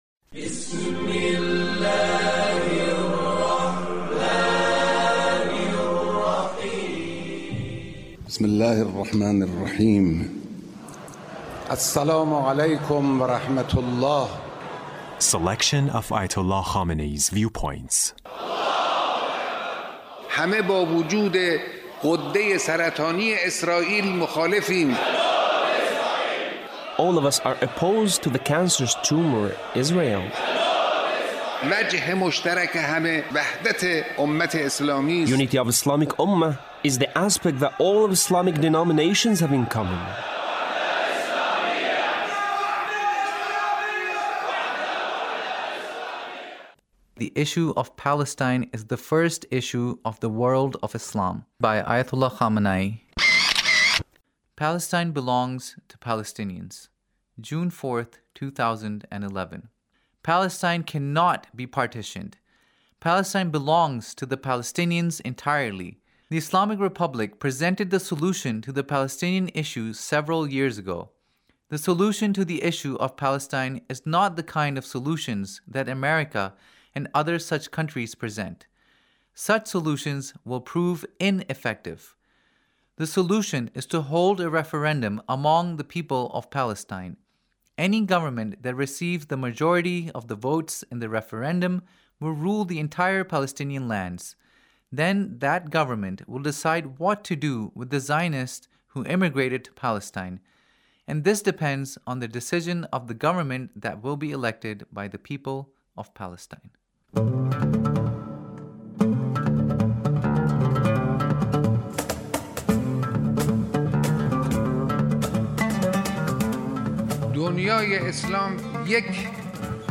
Leader's Speech (1870)